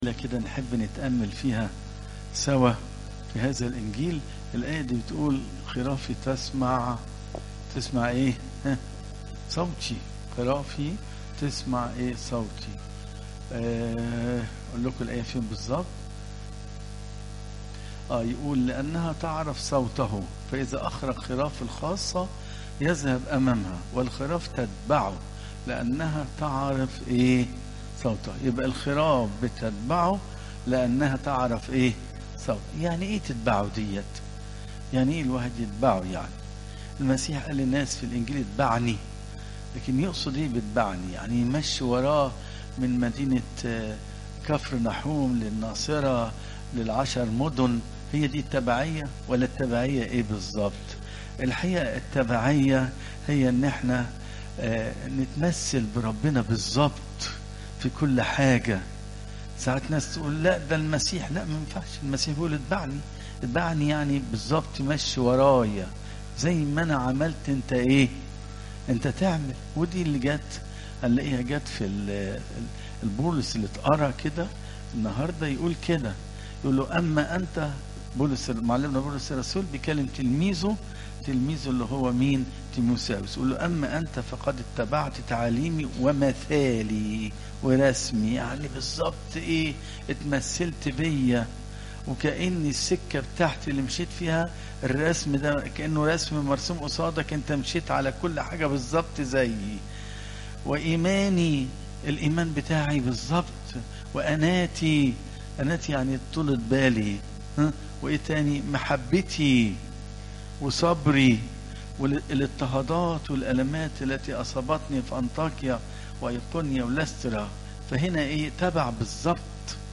عظات قداسات الكنيسة (يو 10 : 1 - 16)